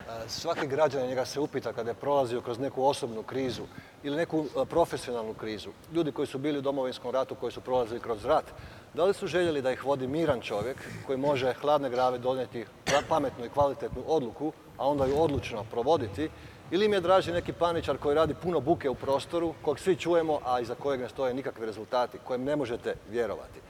Okršaj u zagrebačkoj Esplanadi otkrio je kako izgleda kada se na jednom mjestu okupi deset političkih oponenata. Izdvojili smo zanimljive odgovore kandidata iz višesatnog sučeljavanja.